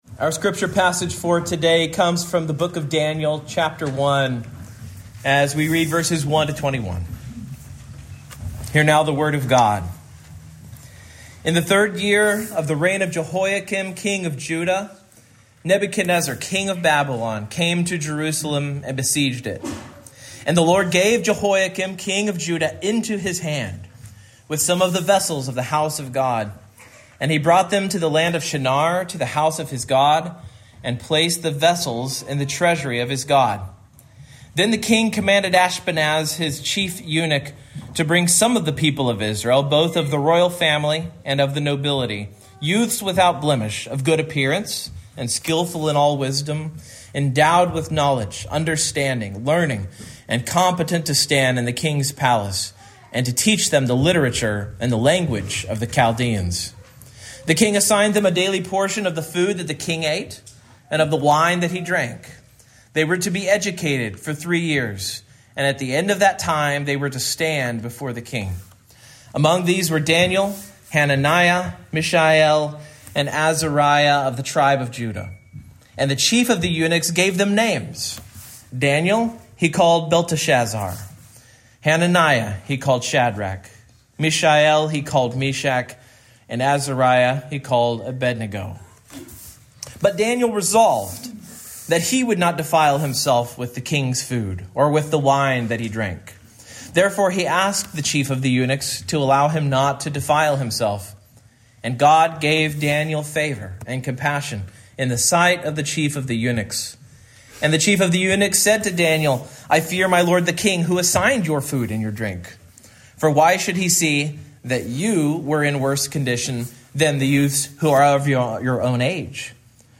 Daniel 1:1-21 Service Type: Morning Main Point